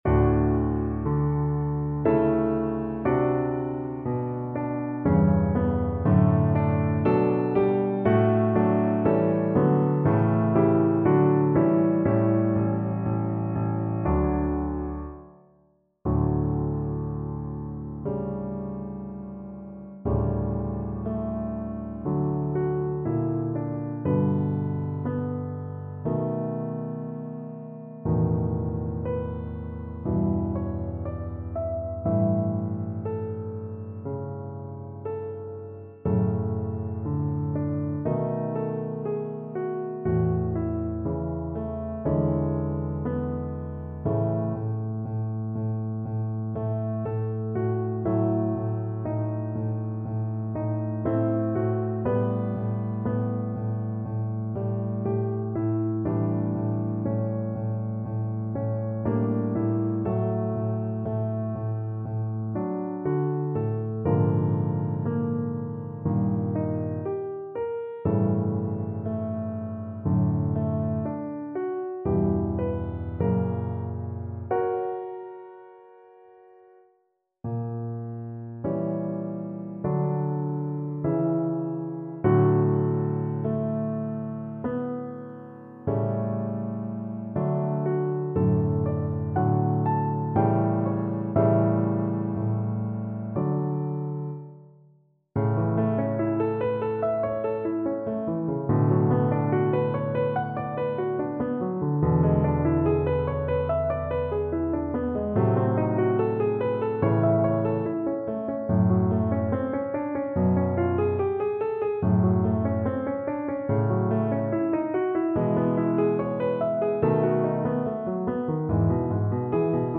Larghetto =60
Classical (View more Classical Cello Music)